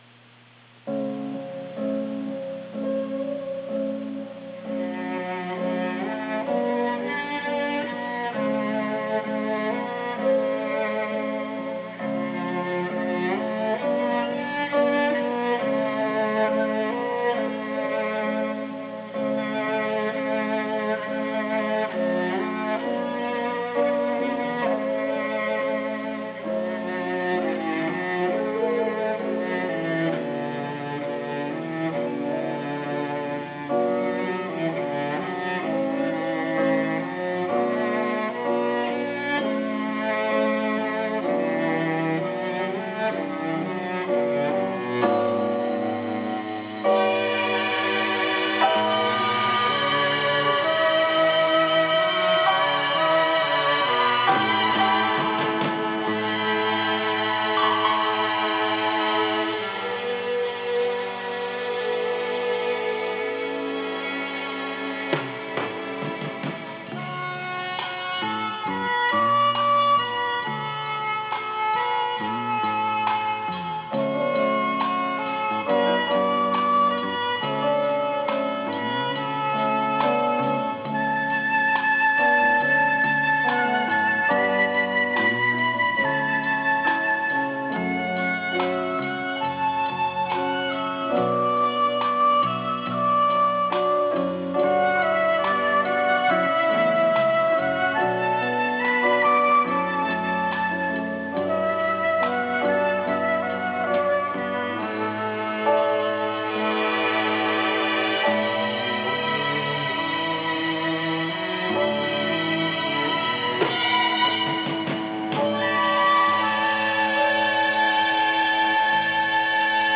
Melody (AU/1MB) - folksong from China